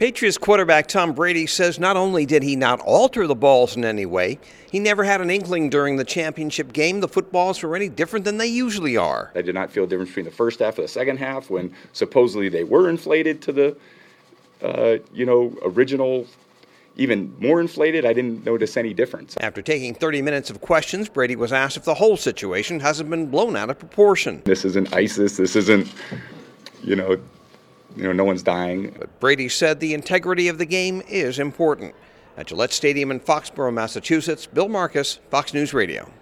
Patriots Quarterback Tom Brady answers questions for a half-hour Thursday about deflated footballs.